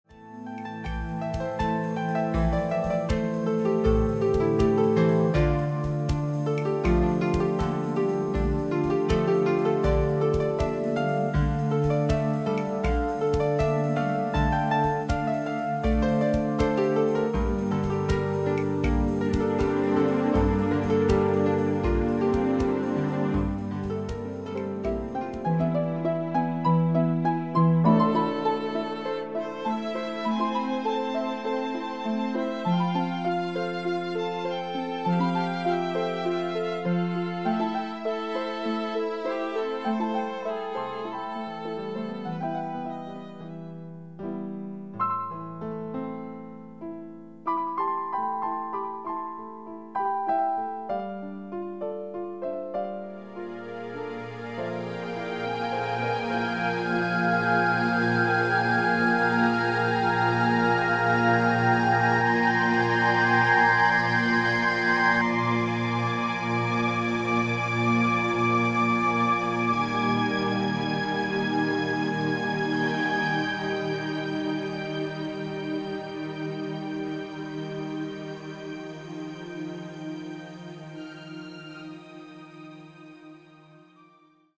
original and relaxing music
Hear a 90 second mix of four tracks